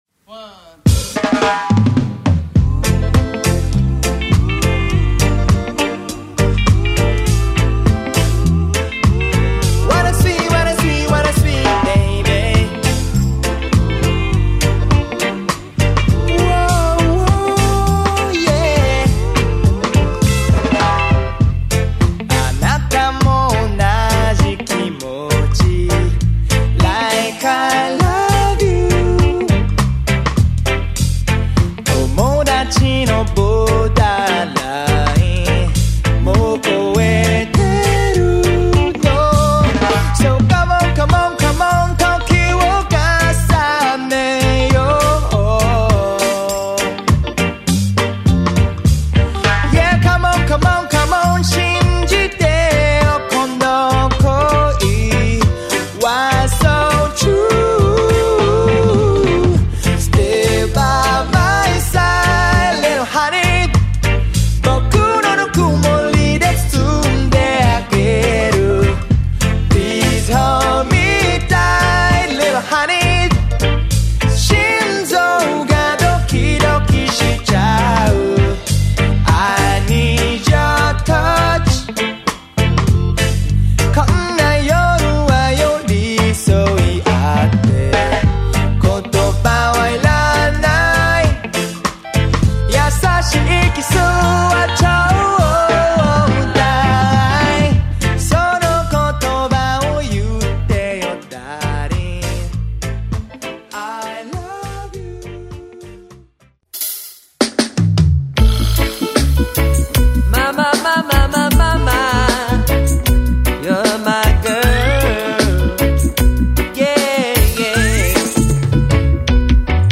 キャッチーでポップなメロディで世界中のレゲエ・ファンの心をつかんだ
ヴィンテージなジャマイカン・サウンドをベースに、
親しみ易いメロディーとソウルフルで情熱的なパフォーマンスを武器に、